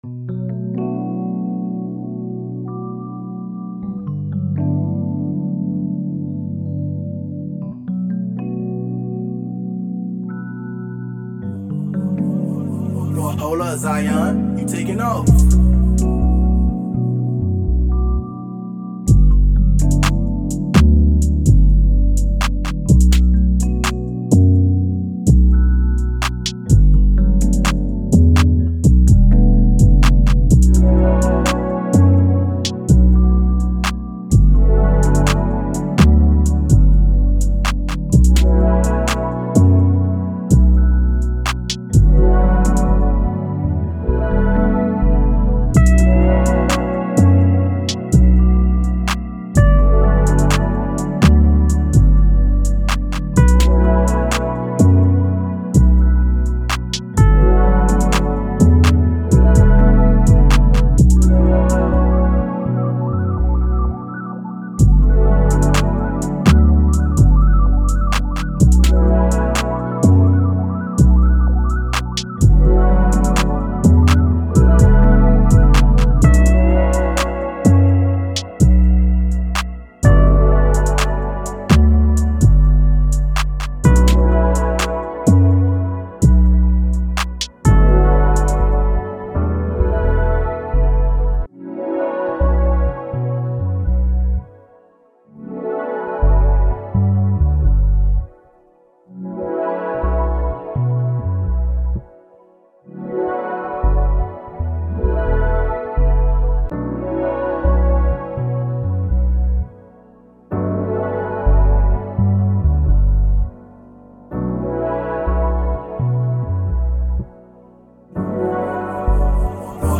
131 A Minor